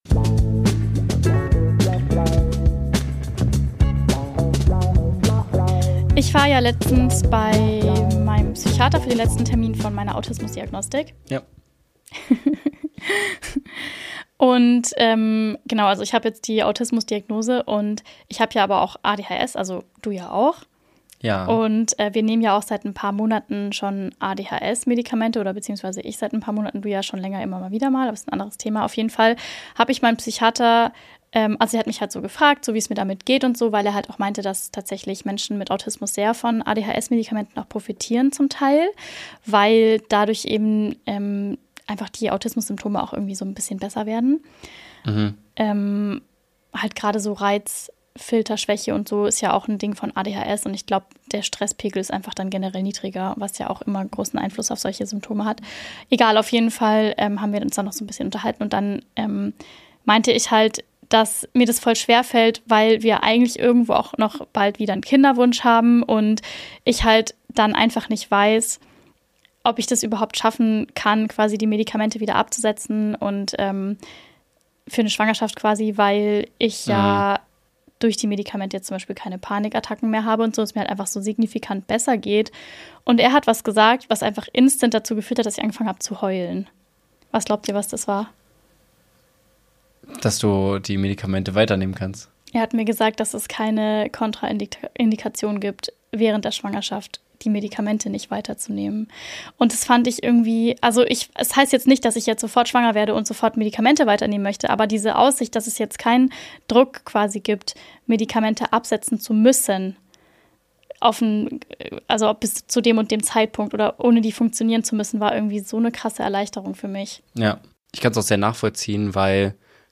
In dieser Folge von Mutti ist die Beste teilen drei ADHSler ihre persönlichen Erfahrungen und geben ehrliche Einblicke in ein Leben mit ADHS – die Höhen, die Tiefen und alles dazwischen.